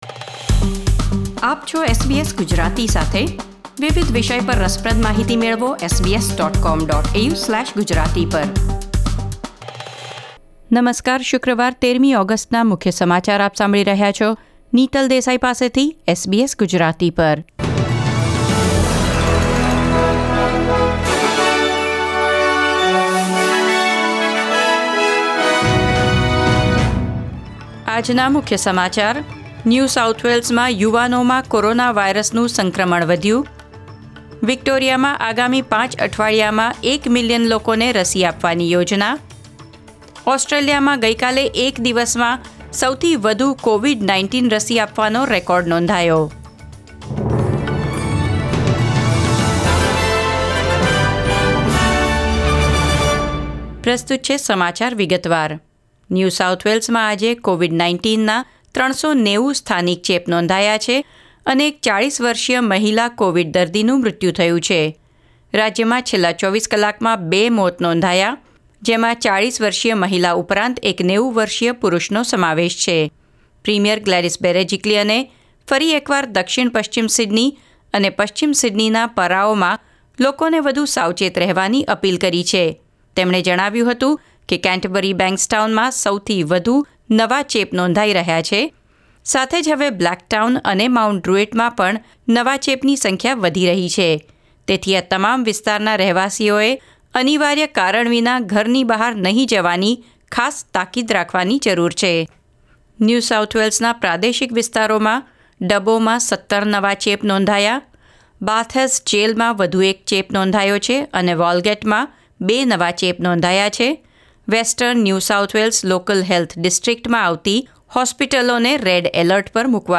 SBS Gujarati News Bulletin 13 August 2021